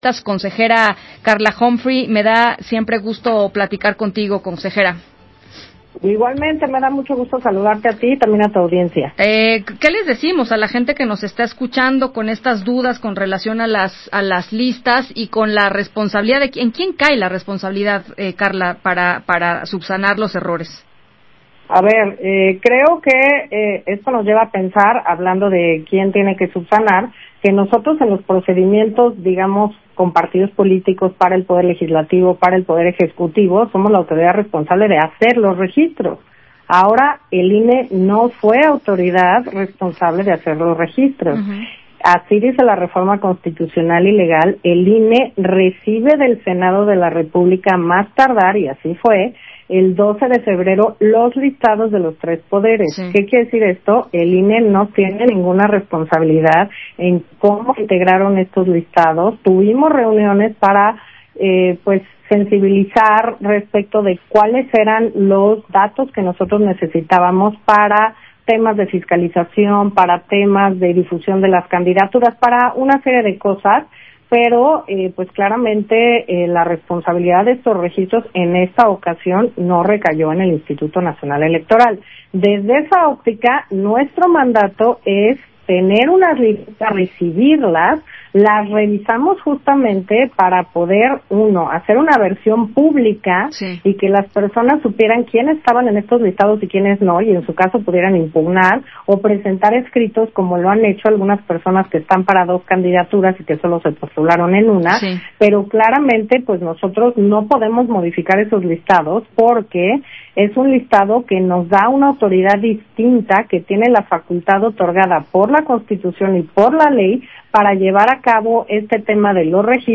Entrevista de la Consejera Electoral Carla Humphrey con Ana Francisca Vega para MVS Radio